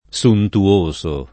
suntuoso [ S untu- 1S o ]